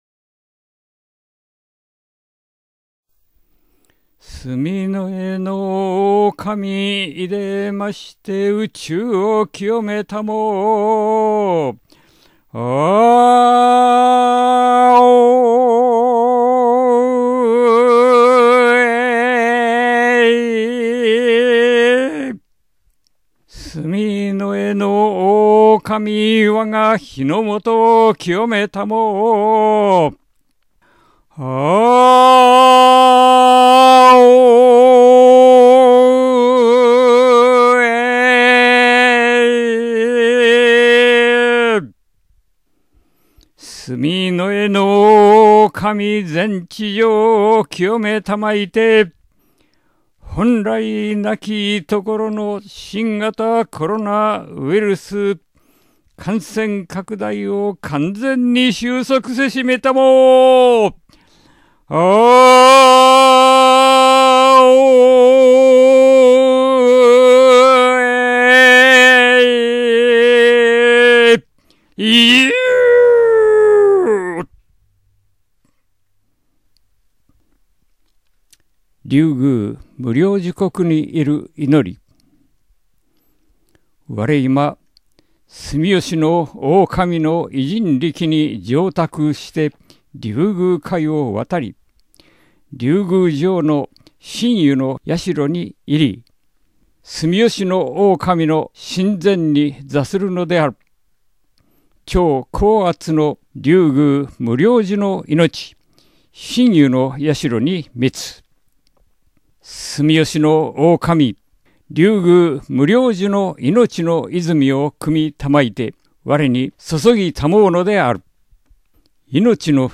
⇒　宇宙浄化・龍宮無量寿国に入る祈り（音声）